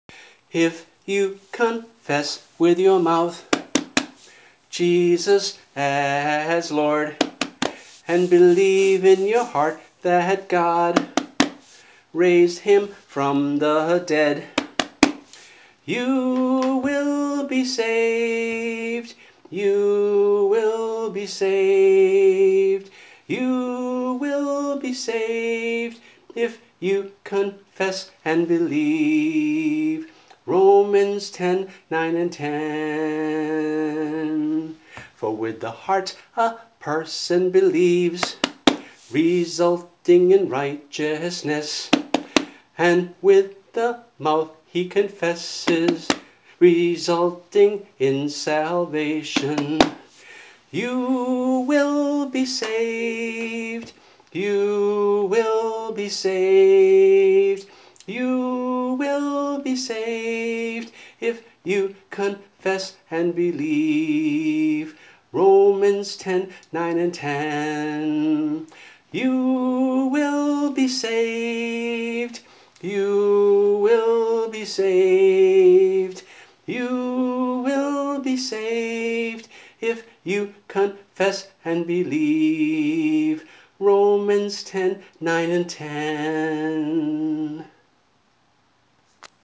[MP3 - voice and guitar]